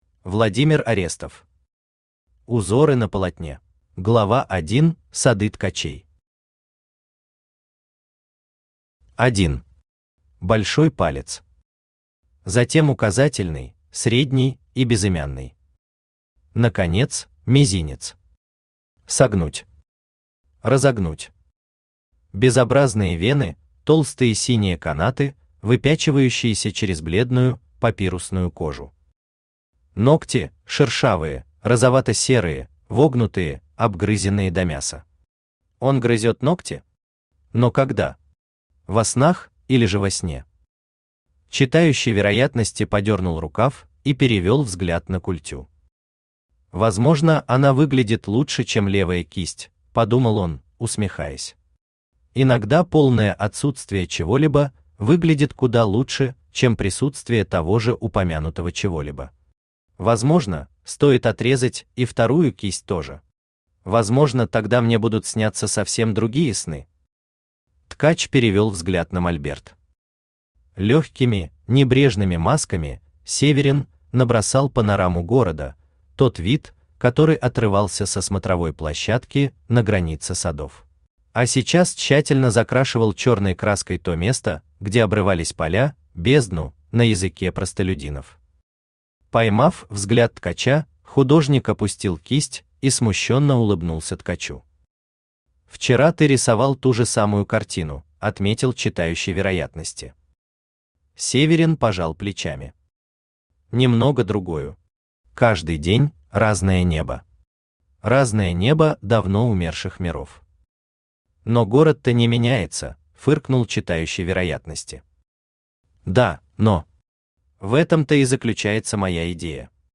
Aудиокнига Узоры на полотне Автор Владимир Орестов Читает аудиокнигу Авточтец ЛитРес.